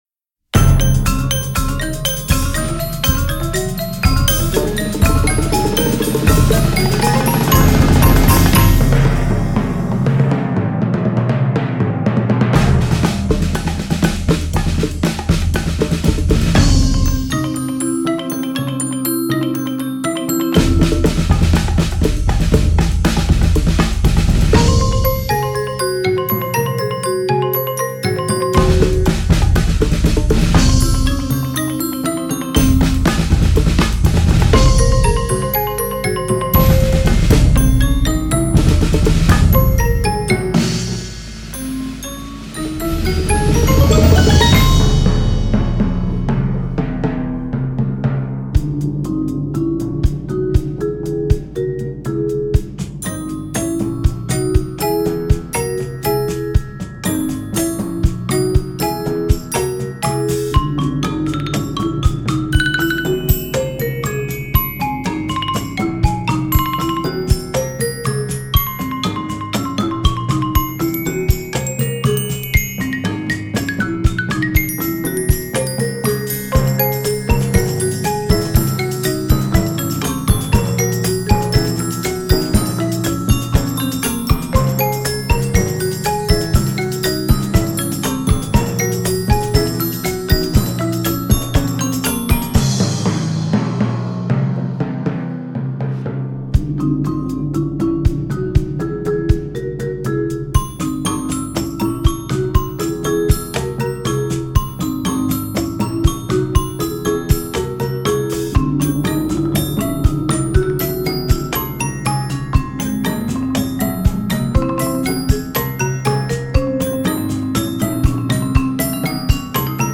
Voicing: 9-12 Percussion